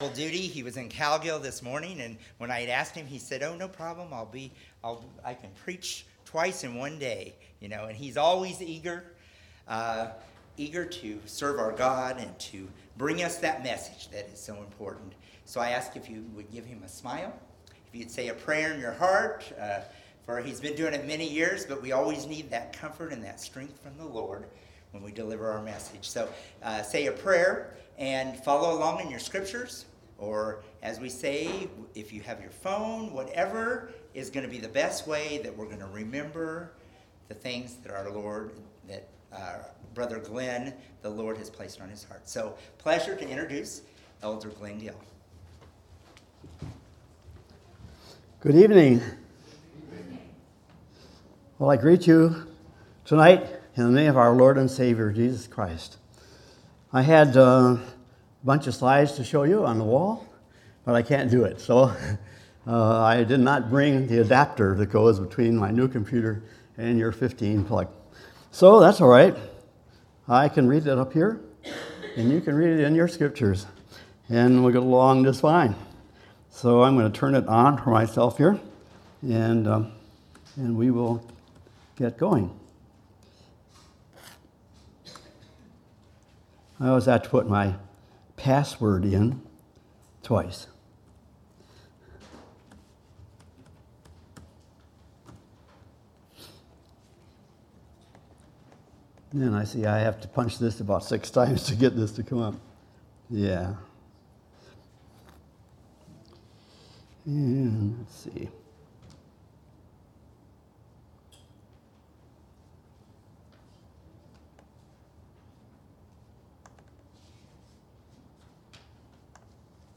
5/26/2019 Location: East Independence Local Event